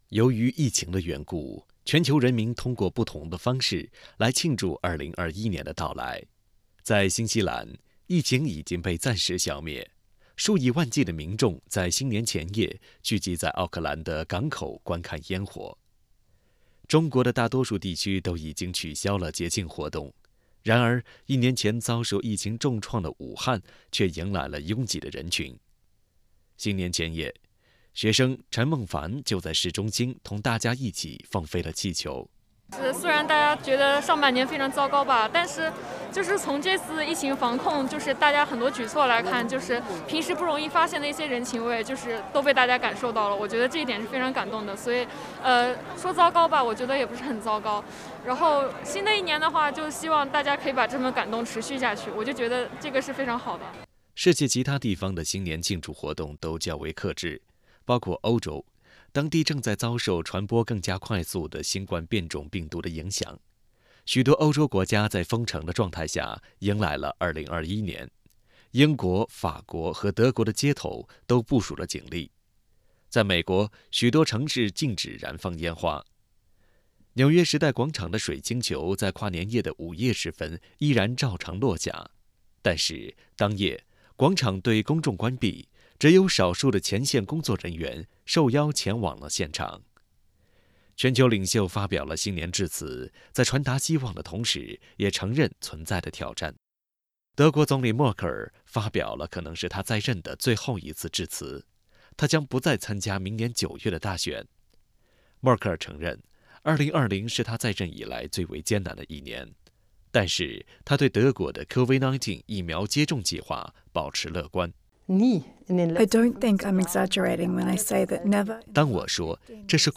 世界不同地区的人们用不同的方式来庆祝新年，但许多活动因疫情的原因被迫取消。世界各国的领袖们表示希望在2021年战胜新冠疫情，他们也同时承认会面临很多困难。(点击图片音频，收听报道。）